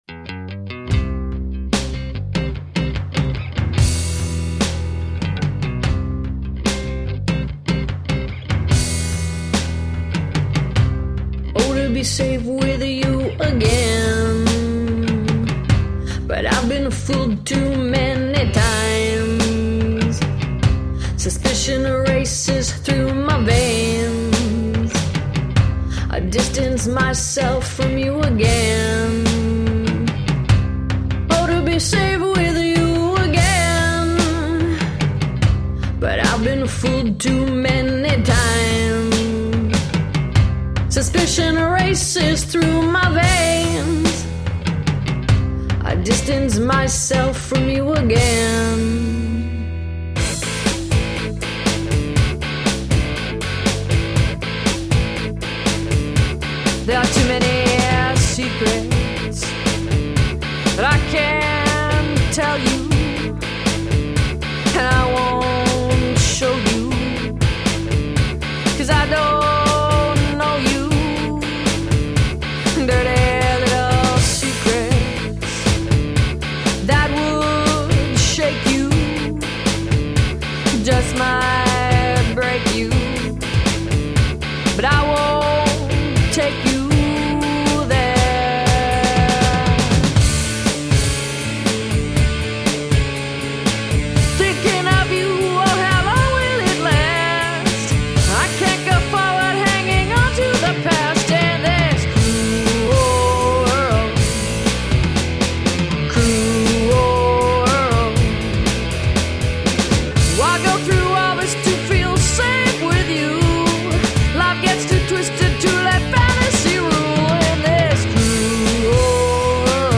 Jazzed up, funk-tinged eclectic pop..